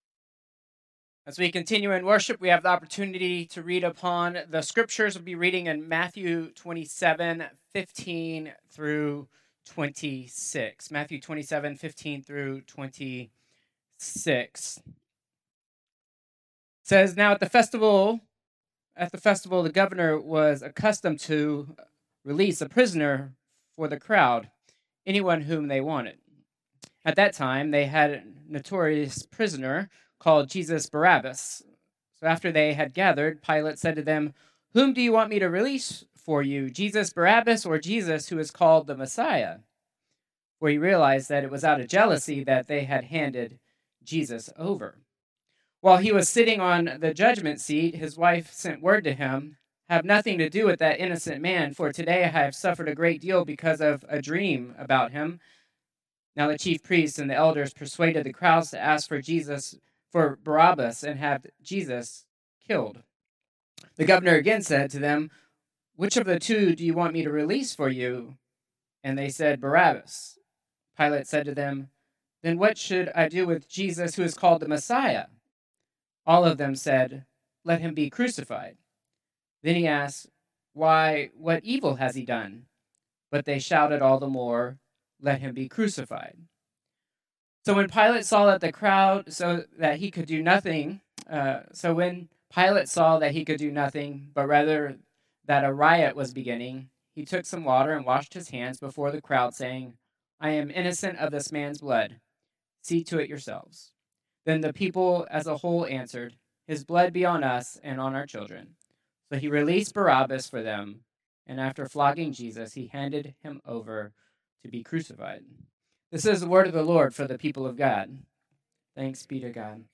Contemporary Worship 3-8-2026